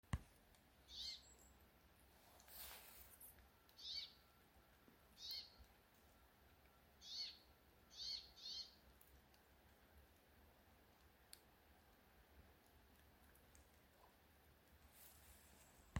Putni -> Žubītes ->
Ziemas žubīte, Fringilla montifringilla